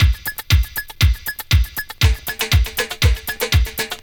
• 119 Bpm Old School Synthpop Drum Beat B Key.wav
Free drum loop sample - kick tuned to the B note. Loudest frequency: 2283Hz
119-bpm-old-school-synthpop-drum-beat-b-key-Jjq.wav